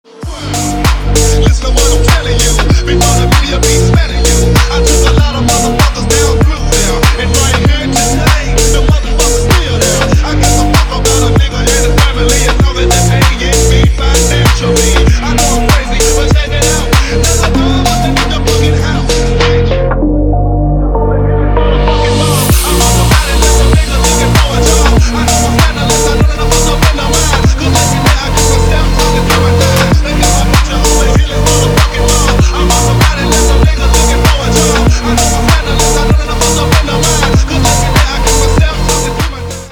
фонк рингтоны